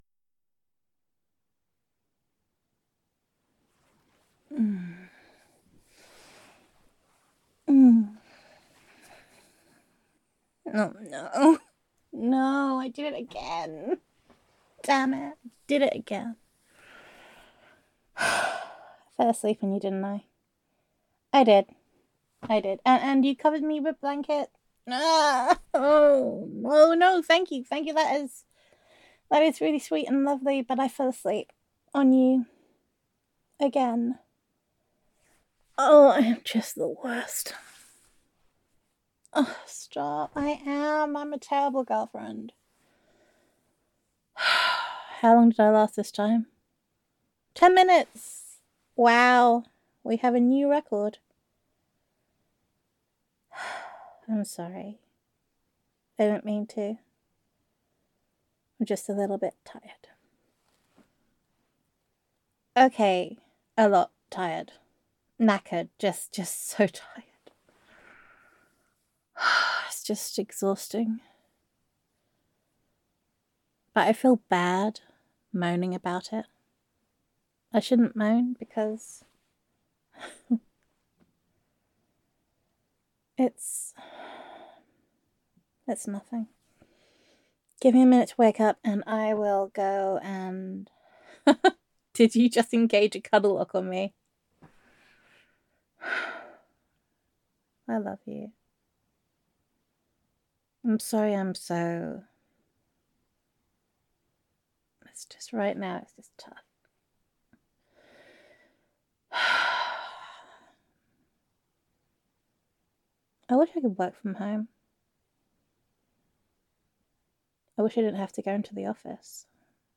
[F4A] Engage Cuddle Lock [Falling Asleep on You, Again][Girlfriend Roleplay][We Have a New Record][Utterly Knackered][I Wish I Could Work From Home][Reverse Comfort][Don’t Call Me Adorable][Domestic Bliss][Meet Rodney the Mouse][Adorable Ranting][Gender Neutral][You Are Concerned Your Girlfriend Is Not Taking Care of Herself]